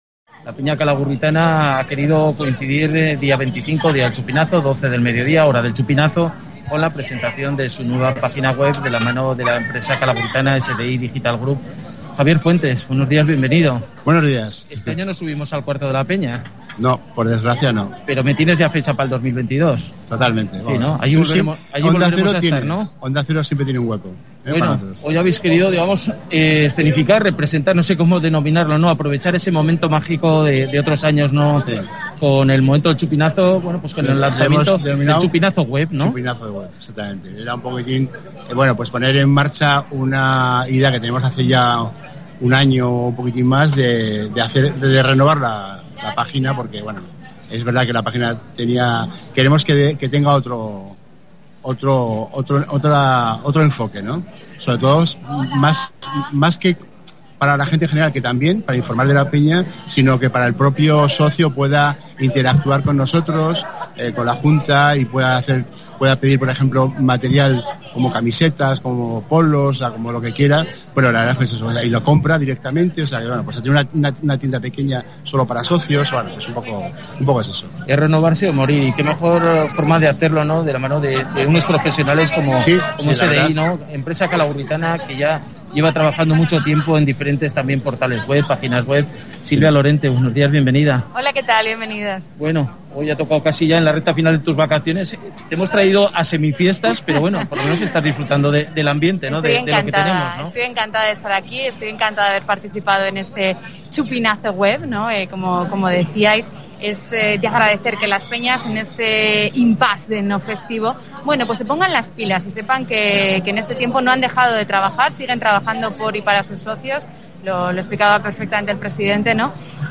Os invitamos a que escuches la entrevista en el programa ‘Más de uno’ de Onda Cero, en el que hablamos de proyectos web y de la implicación de SDi con Calahorra.
Entrevista-en-Onda-Cero-Chupinazo-Web.m4a